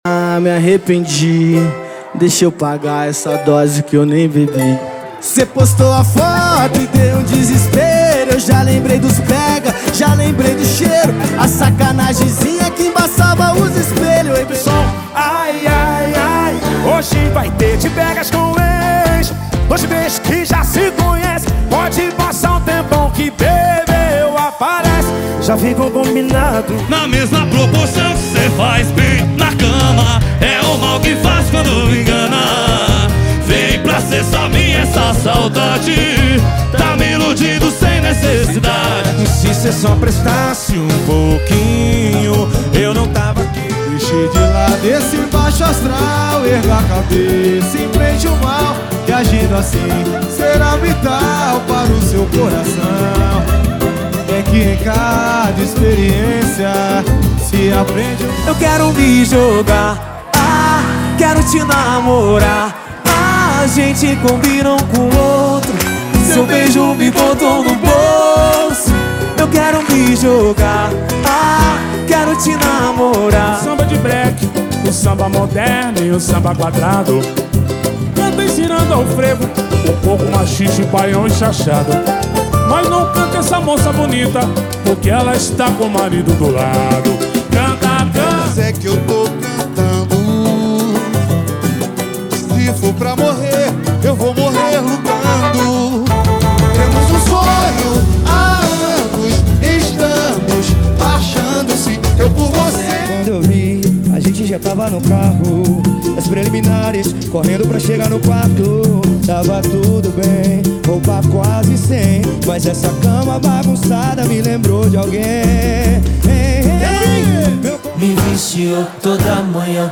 • Pagode e Samba = 50 Músicas
• Sem Vinhetas
• Em Alta Qualidade